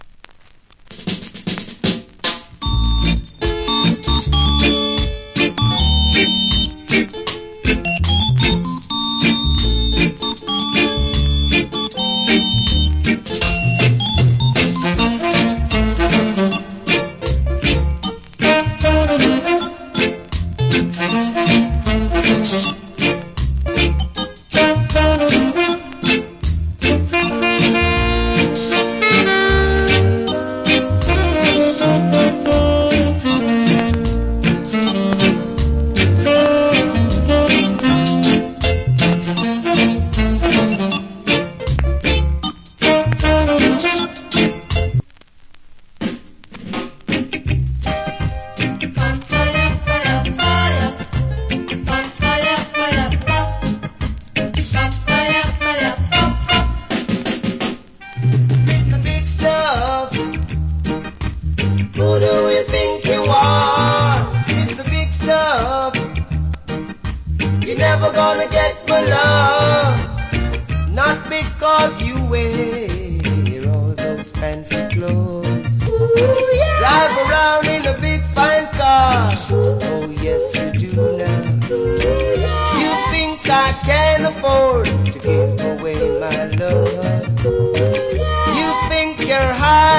Great Reggae Inst.